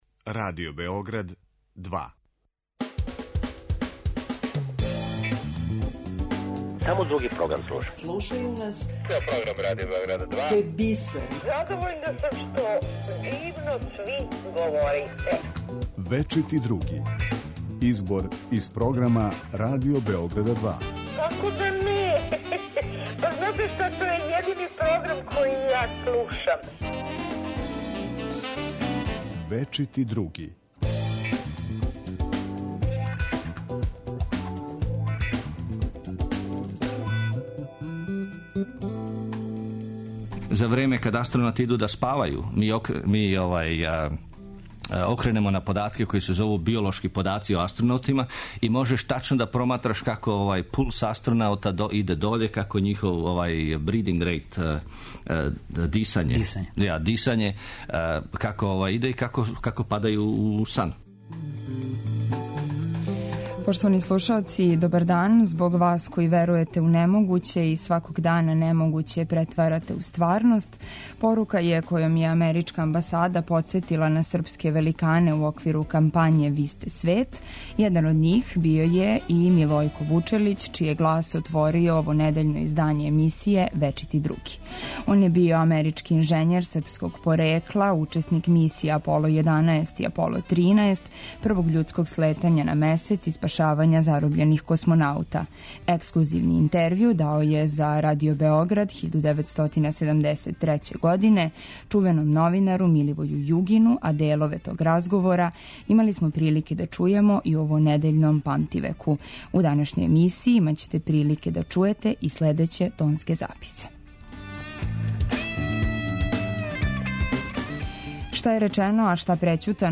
Избор из програма Радио Београда 2